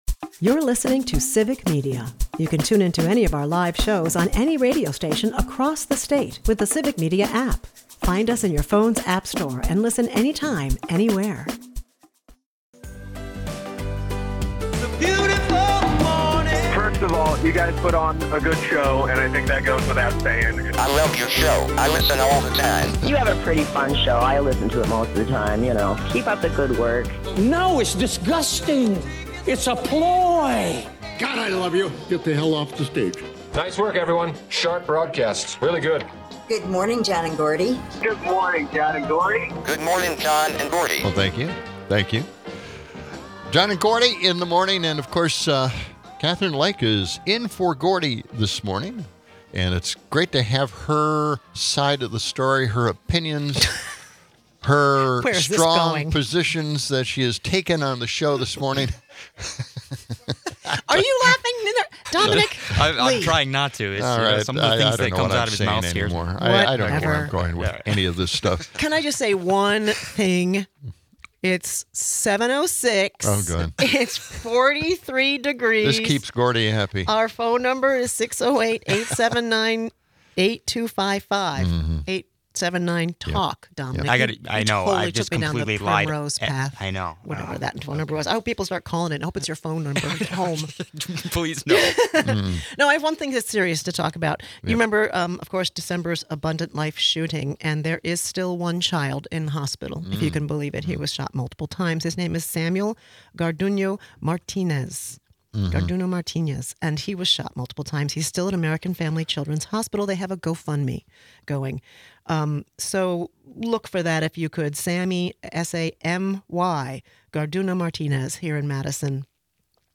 Legal expert Jim Santelle joins to dissect ongoing national legal battles, emphasizing the chaotic state of U.S. politics and law.